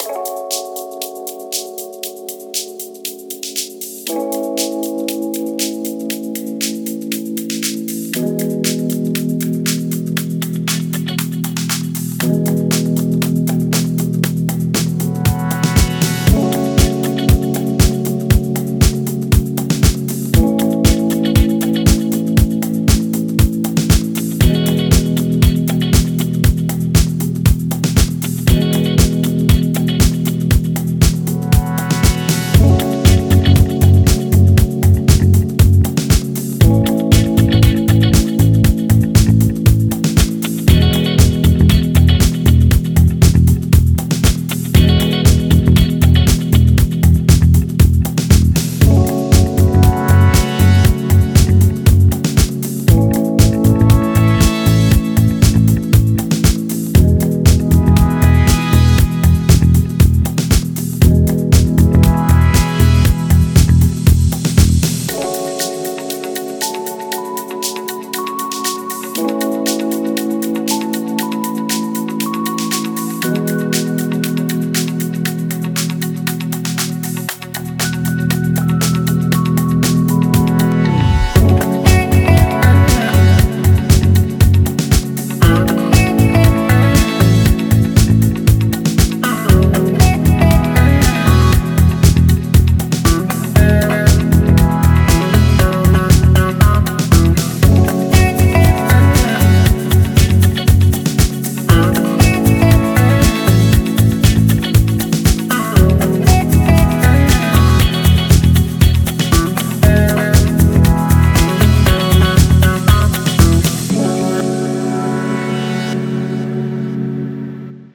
Genre: poprock.